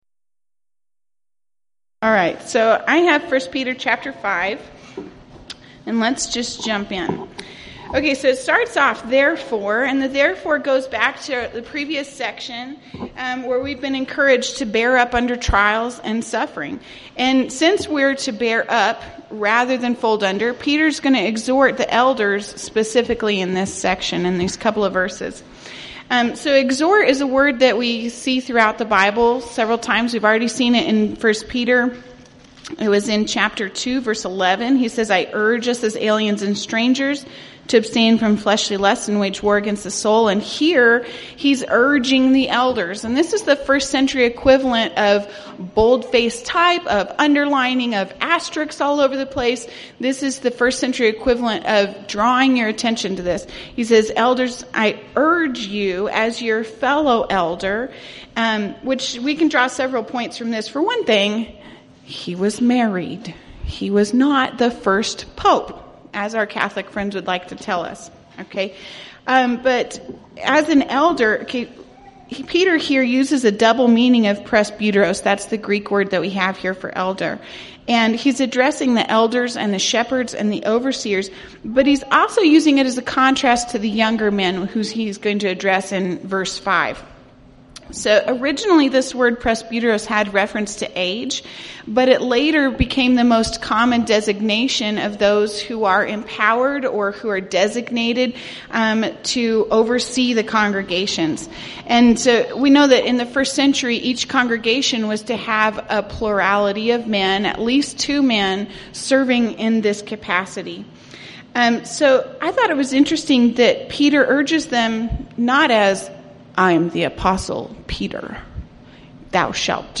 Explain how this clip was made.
Event: 3rd Annual Texas Ladies in Christ Retreat